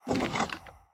Minecraft Version Minecraft Version 1.21.5 Latest Release | Latest Snapshot 1.21.5 / assets / minecraft / sounds / mob / wither_skeleton / idle1.ogg Compare With Compare With Latest Release | Latest Snapshot